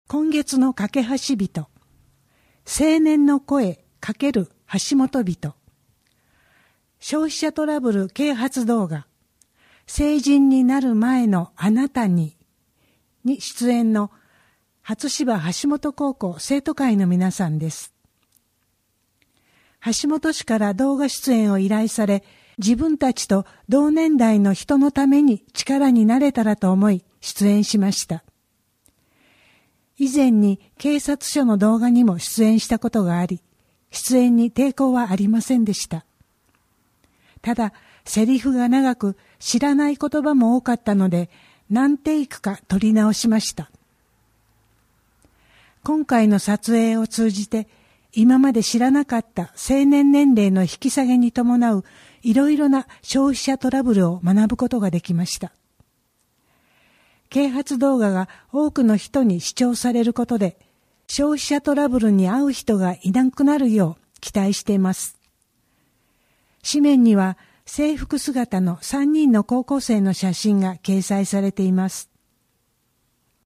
WEB版　声の広報 2023年5月号